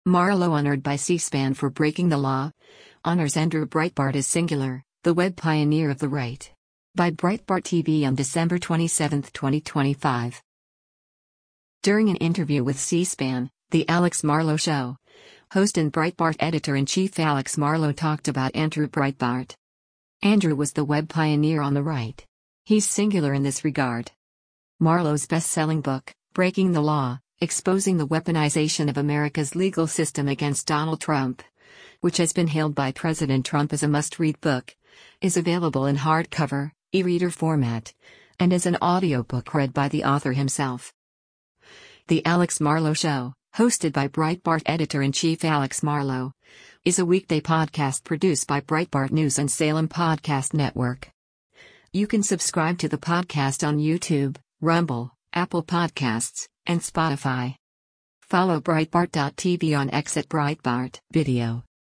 During an interview with C-SPAN, “The Alex Marlow Show,” host and Breitbart Editor-in-Chief Alex Marlow talked about Andrew Breitbart.